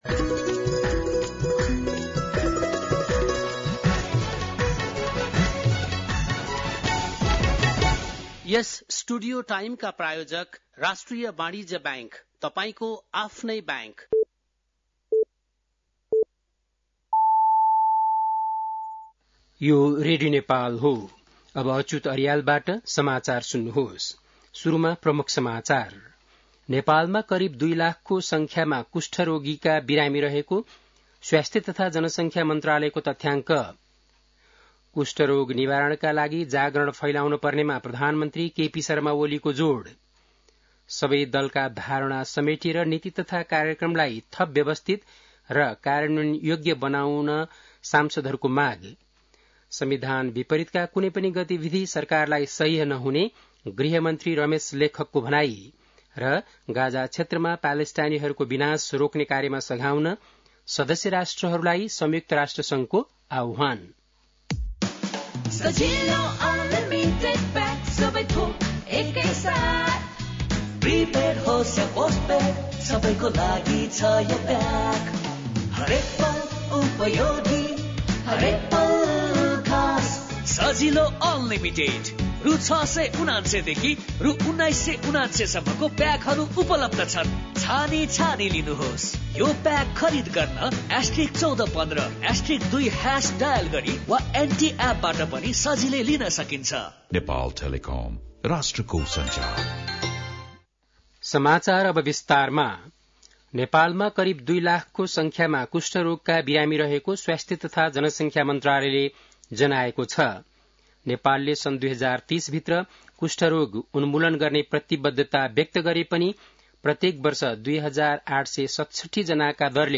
बेलुकी ७ बजेको नेपाली समाचार : २५ वैशाख , २०८२
7-pm-nepali-news-.mp3